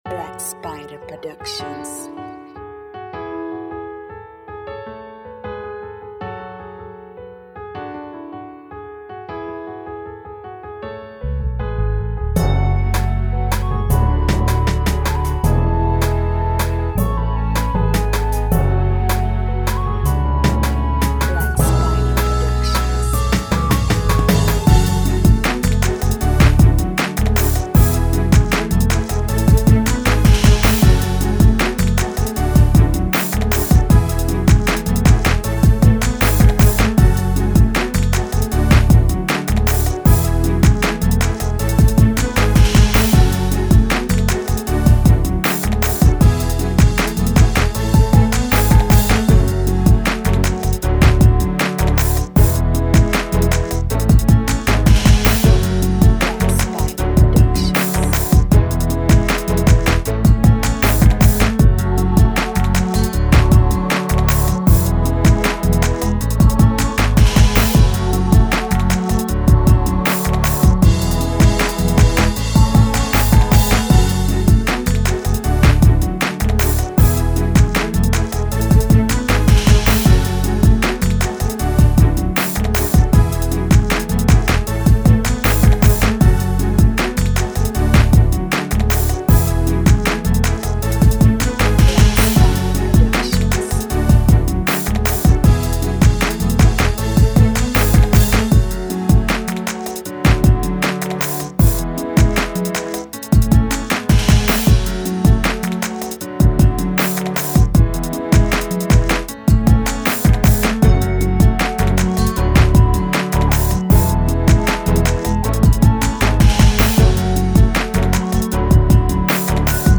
Riddim Instrumental